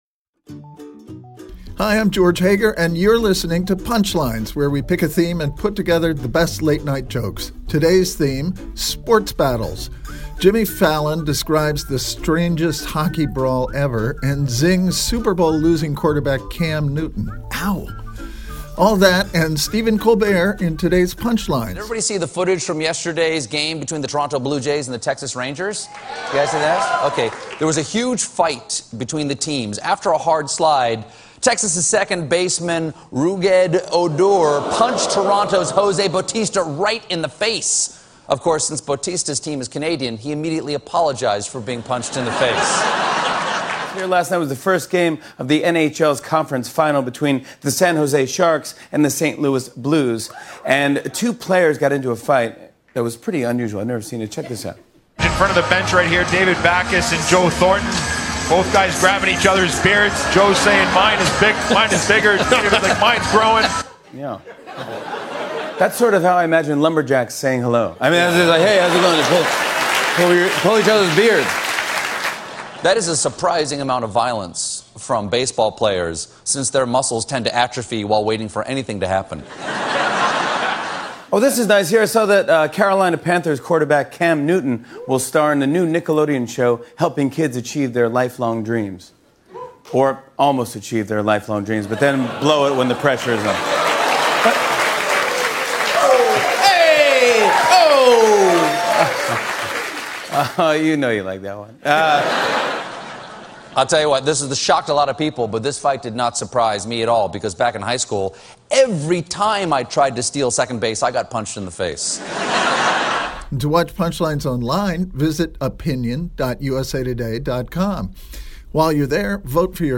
The late-night comics on the latest in sports news including baseball and hockey brawls.